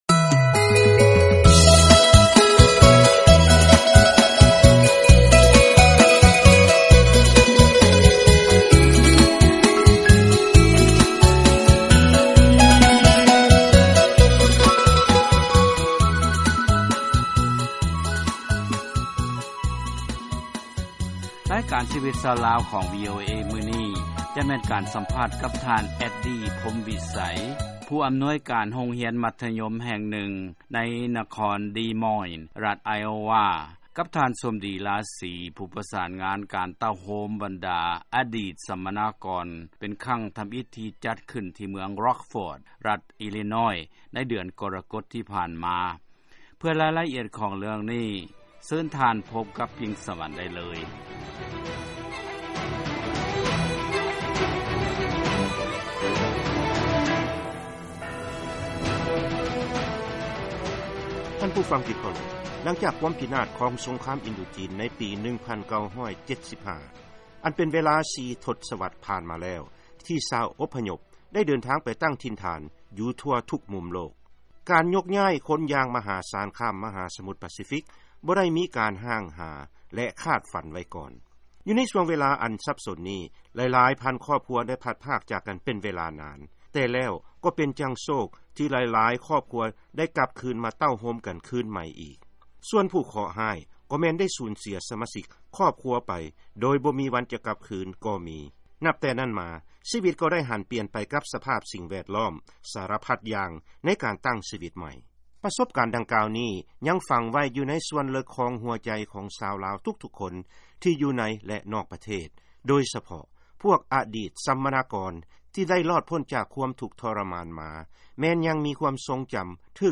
ຟັງລາຍການສຳພາດ ການຊຸມນຸມ ອະດີດບັນດາສຳມະນາກອນລາວ ໃນ ສະຫະລັດ